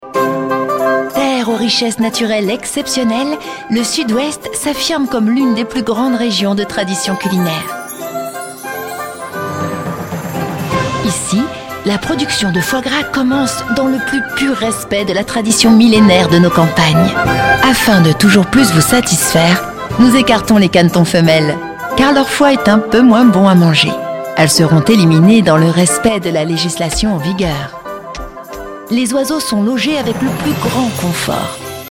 Voix-off féminine pro, voix fraiches, mutines, complices ou chantantes.
Foie-Gras-parodie-ironique-legere-un-peu-snob.mp3